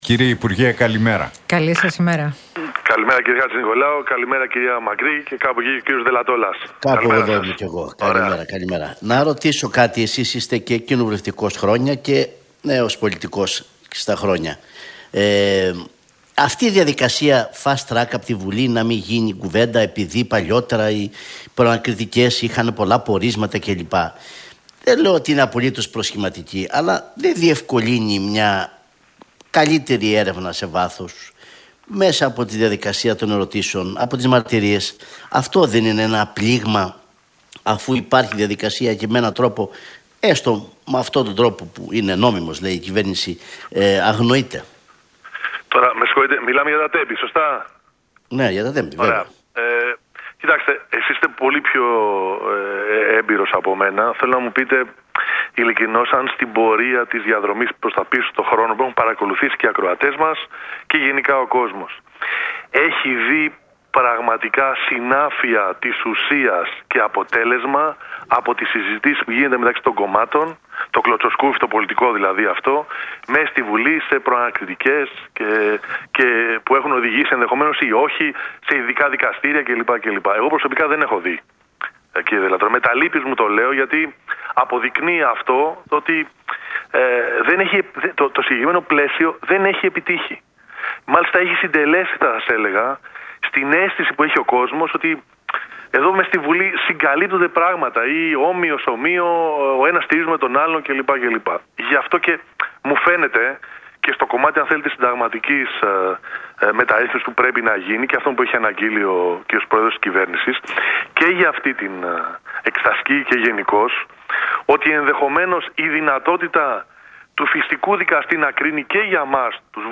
σε συνέντευξή του στους Νίκο Χατζηνικολάου